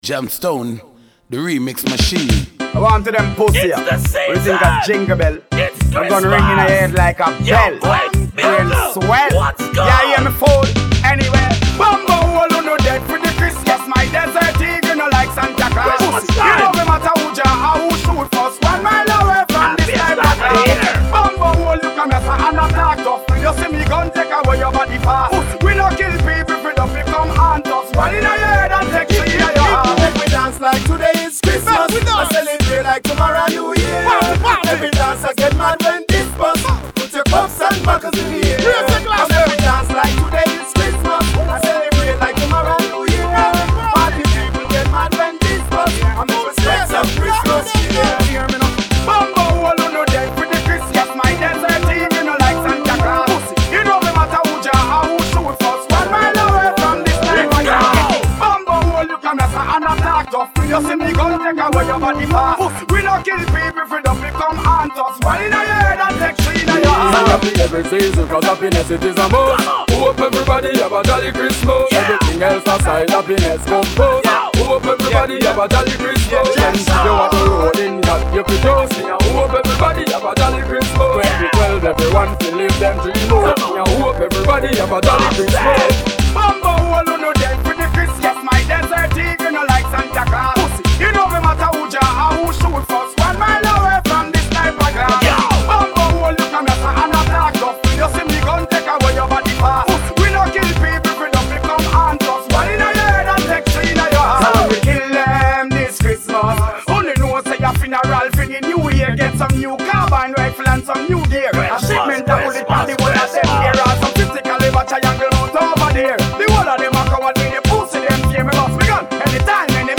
Vocal track